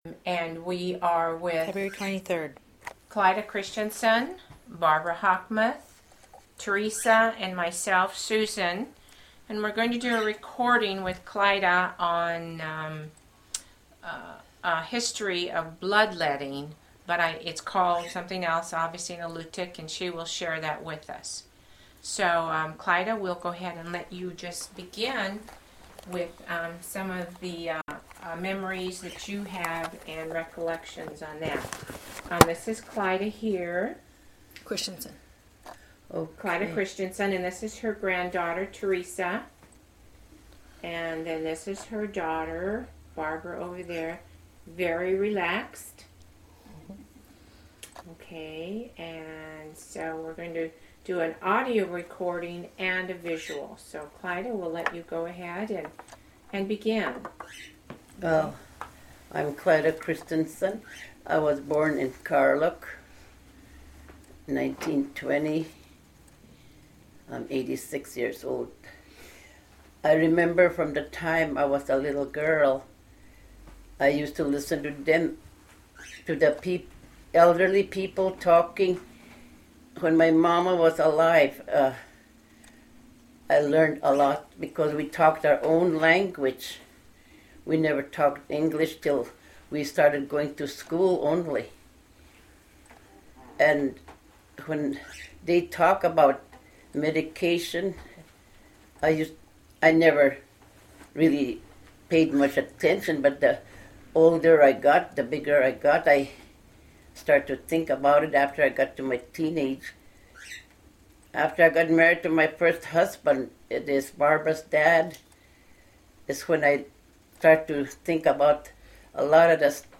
Original Format: MiniDisc
Cultural Narrative